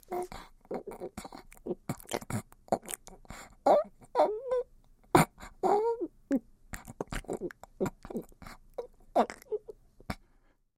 Здесь вы можете скачать или послушать онлайн странные шумы, шаги и другие аудиофрагменты.
Шум праздничного разгула домового во сне